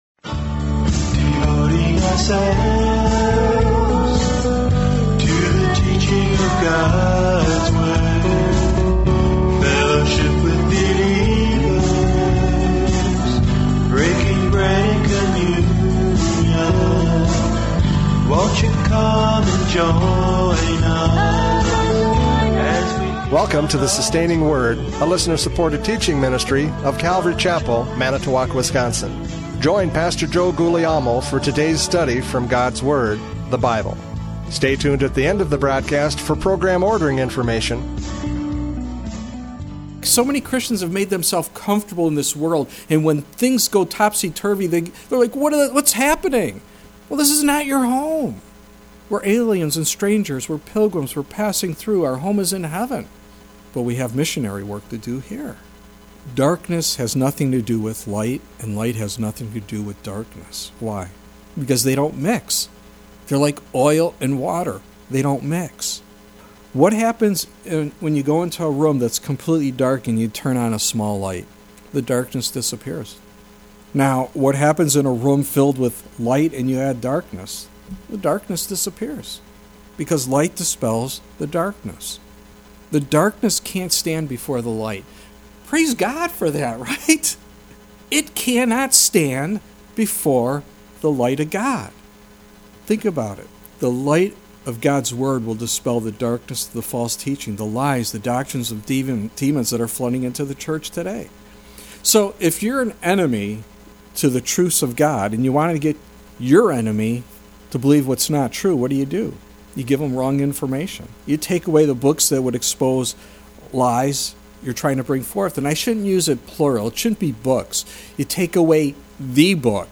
John 8:12-20 Service Type: Radio Programs « John 8:12-20 Light and Darkness!